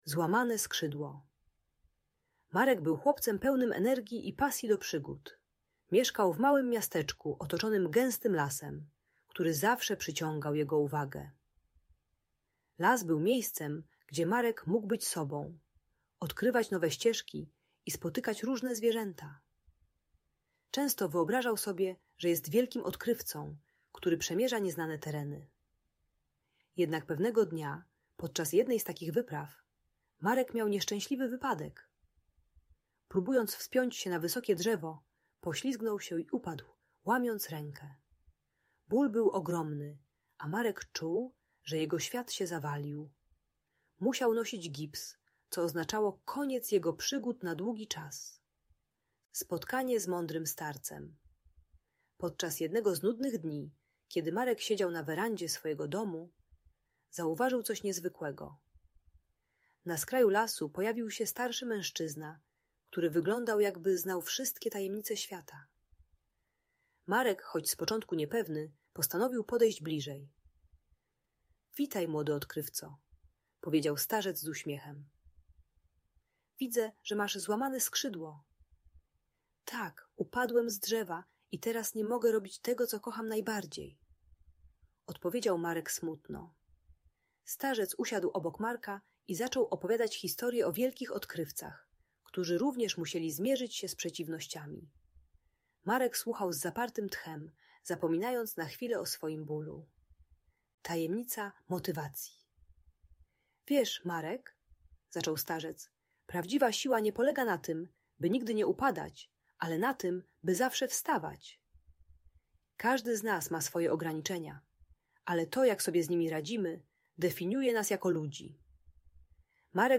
Złamane Skrzydło - Szkoła | Audiobajka